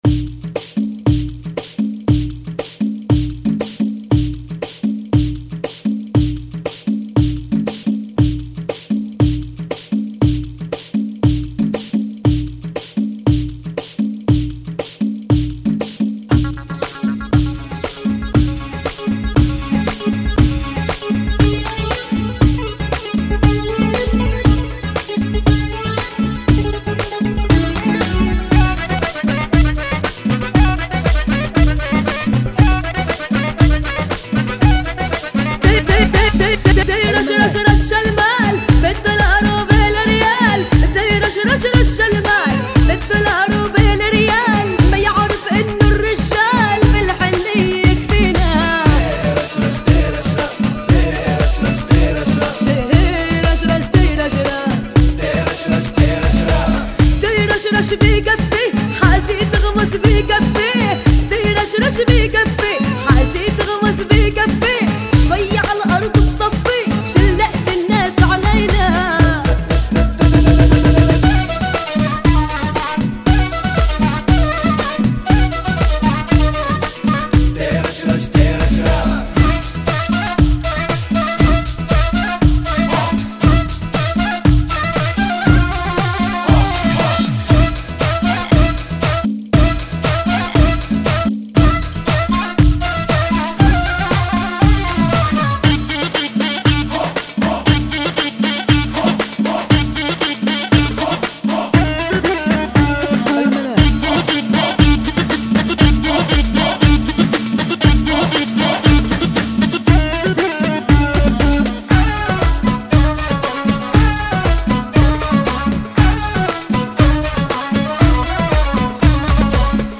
[ 118 Bpm ]